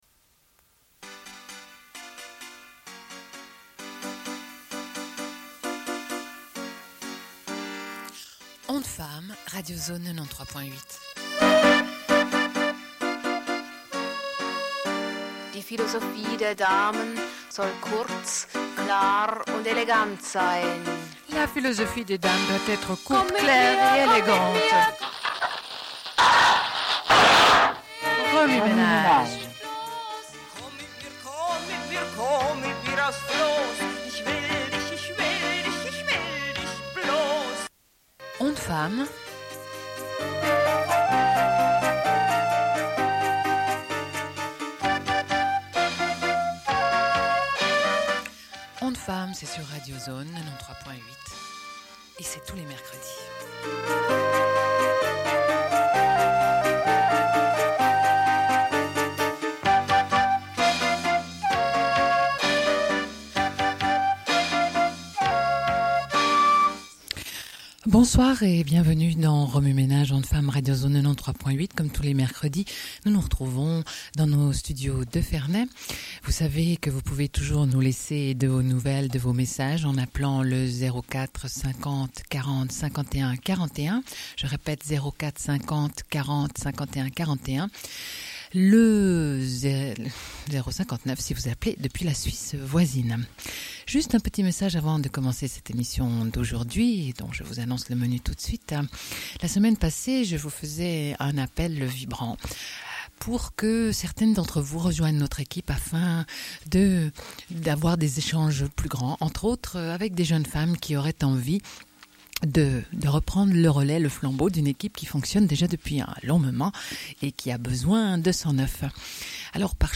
Appel pour les droits des femmes afghanes et lecture de poèmes pachtounes. Témoignage d'une enseignante burkinabée.